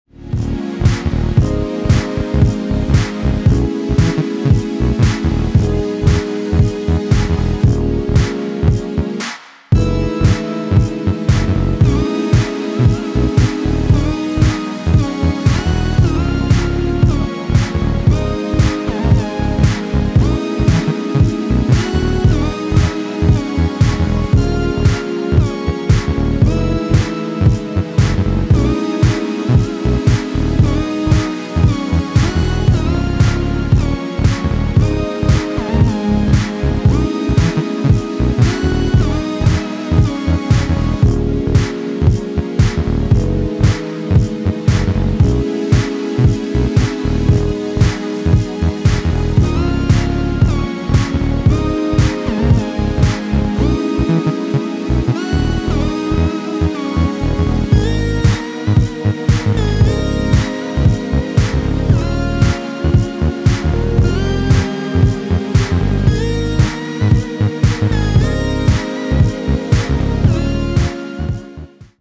スペーシーなシンセワークと這うにファンクするベースラインを駆使しクールなビートダウン・ハウス/ブギーを展開していったA面